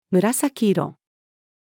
紫色-female.mp3